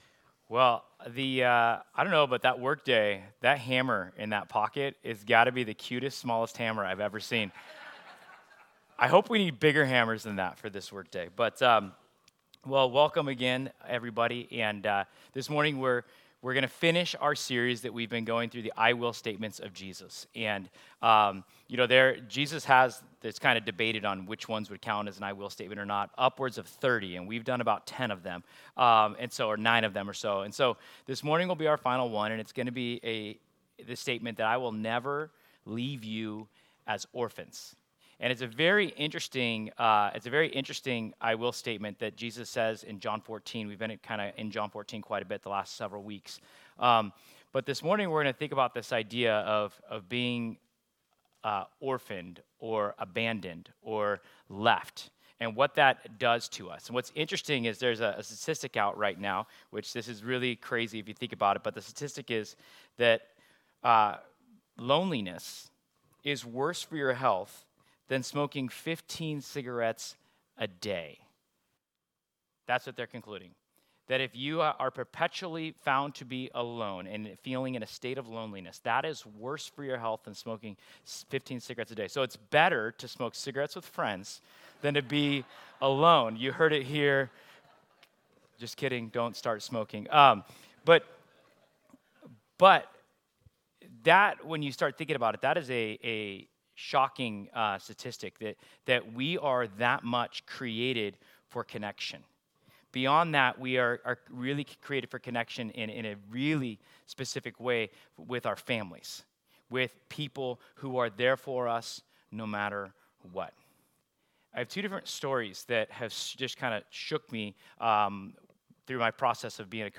Passage: John 14:18 Service Type: Sunday This week, we’ll explore one of the deepest — and often unrealized — human needs: the need to belong.